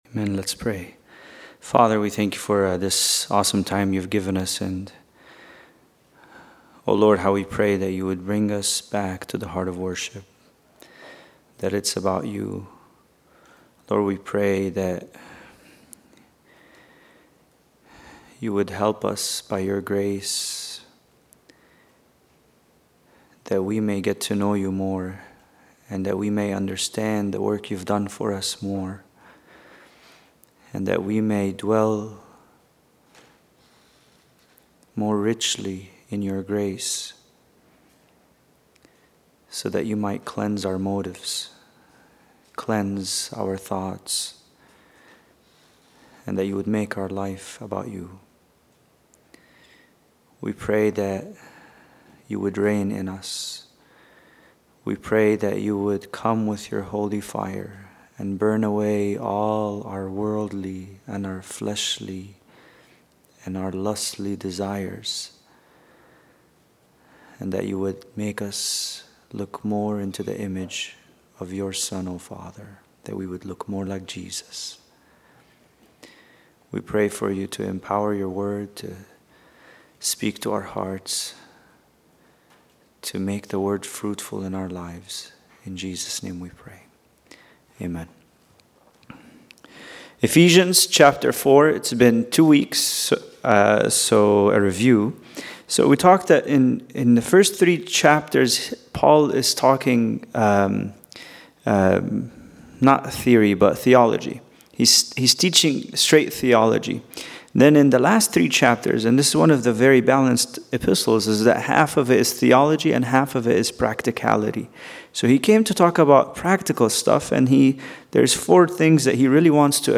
Bible Study: Ephesians 4:7-11